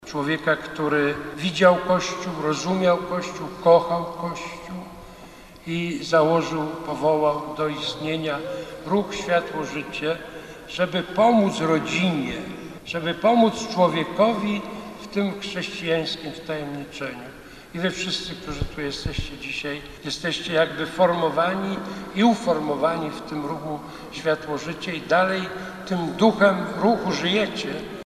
Podczas homilii kardynał Kazimierz Nycz przypomniał najważniejszy cel ruchu, który postawił założyciel ksiądz Franciszek Blachnicki.